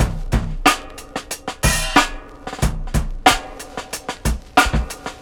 • 92 Bpm Drum Loop Sample C# Key.wav
Free breakbeat sample - kick tuned to the C# note. Loudest frequency: 2439Hz
92-bpm-drum-loop-sample-c-sharp-key-1y3.wav